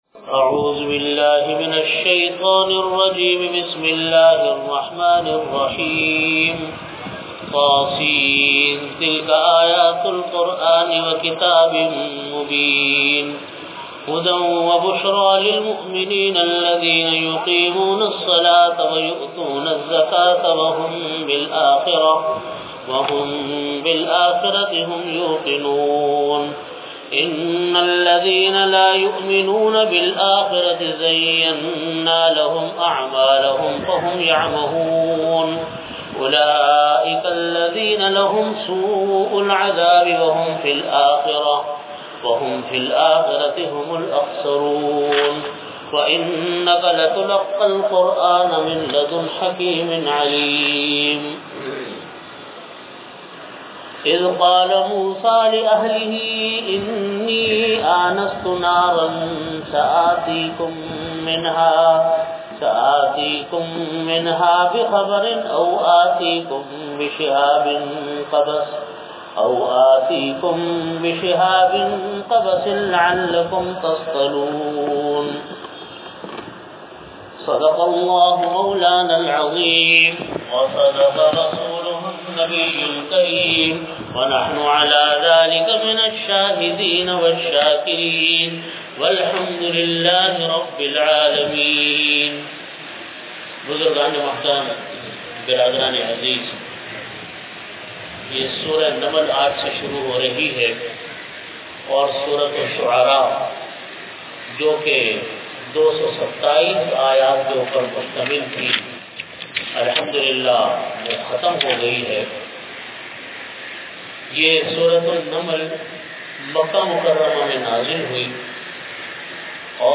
Delivered at Jamia Masjid Bait-ul-Mukkaram, Karachi.
Tafseer · Jamia Masjid Bait-ul-Mukkaram, Karachi